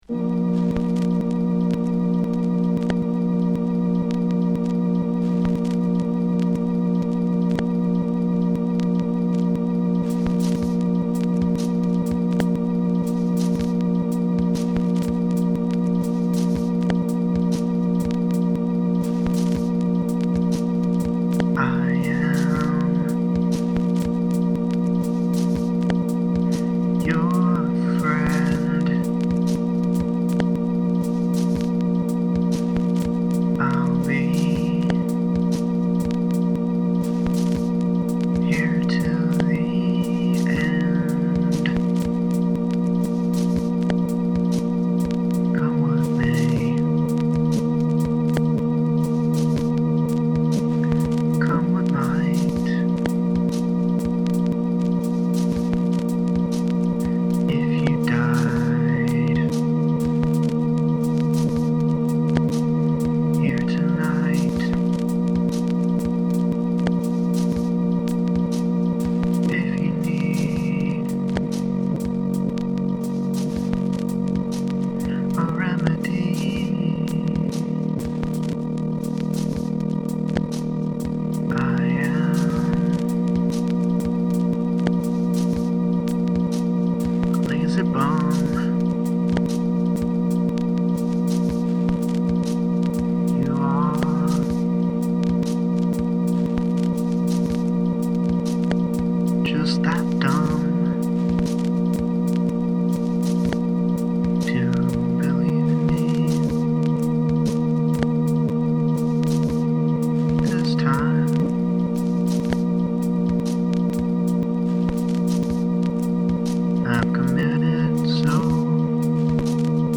dance/electronic
Folk
World music